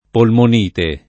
polmonite [ polmon & te ] s. f.